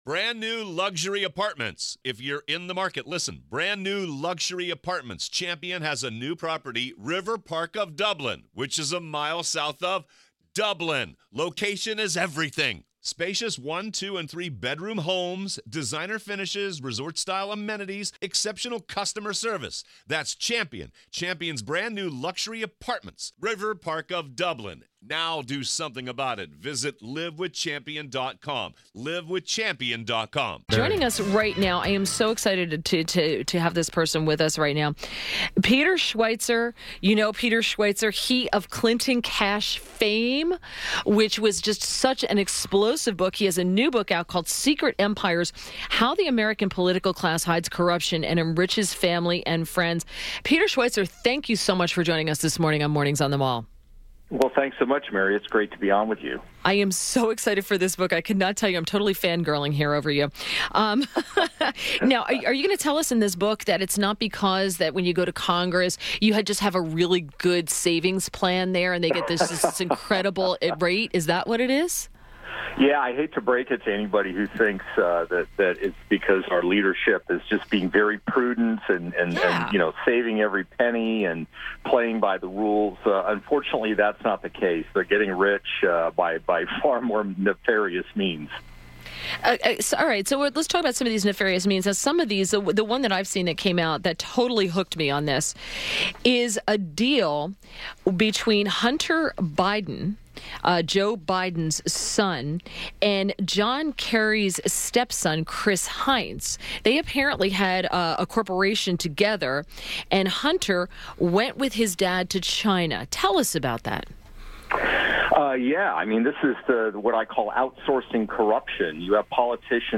WMAL Interview - PETER SCHWEIZER - 03.22.18